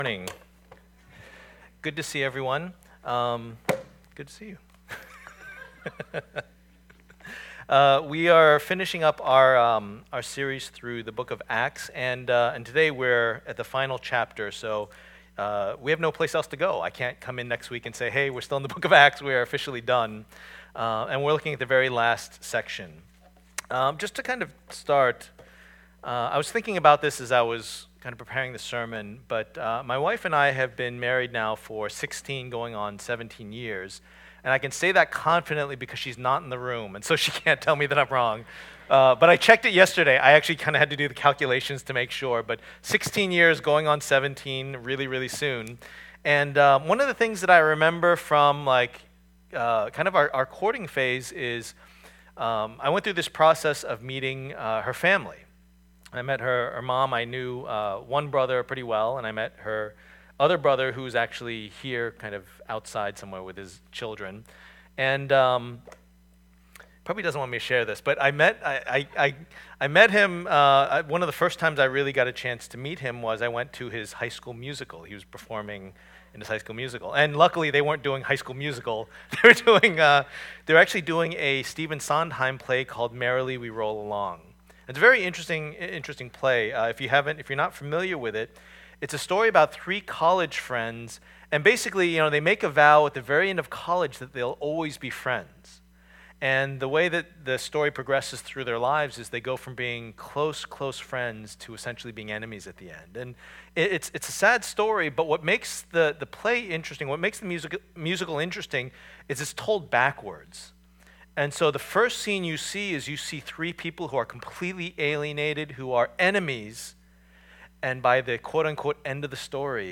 Acts 28:11-31 Service Type: Lord's Day %todo_render% « Paul’s Defense